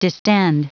Prononciation du mot distend en anglais (fichier audio)
Prononciation du mot : distend